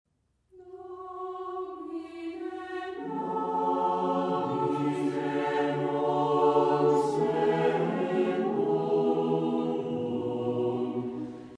• Flautas
• Vihuela de arco
• Vihuela de péñola
• Órgano
Piezas sacras